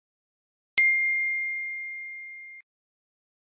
iphone-notif.mp3